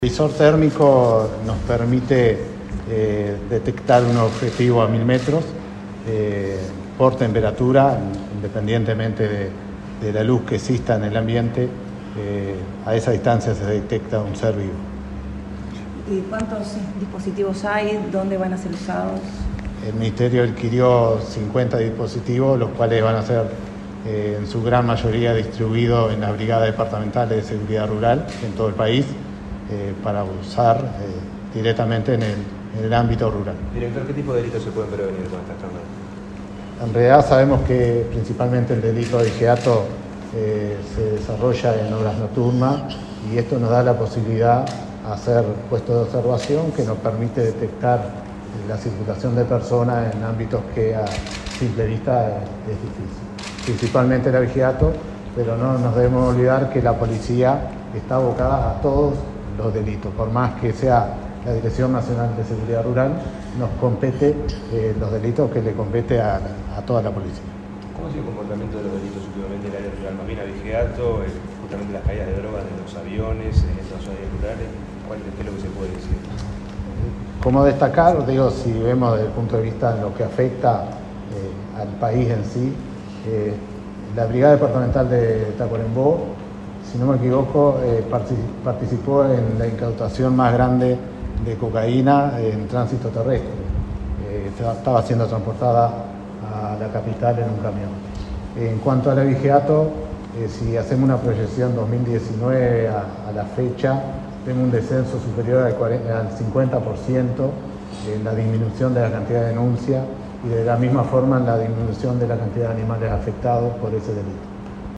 Declaraciones del director nacional de Seguridad Rural, Williard González